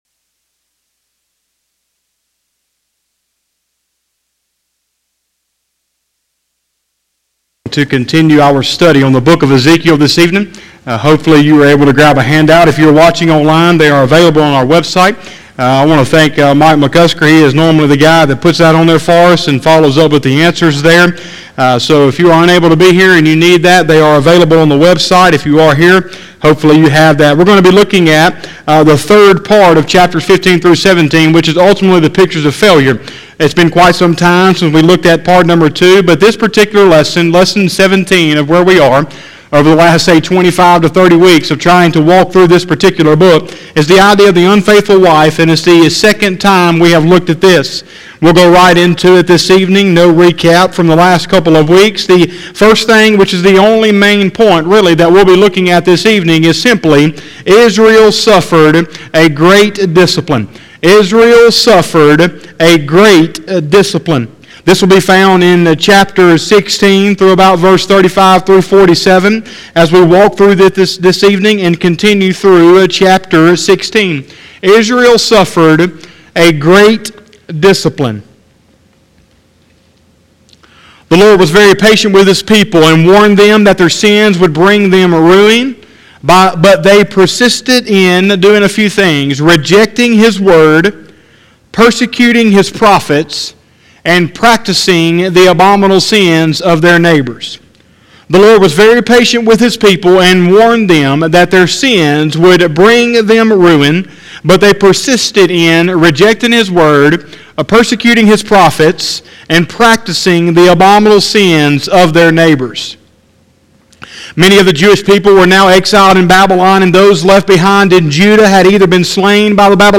01/03/2021 – Sunday Evening Service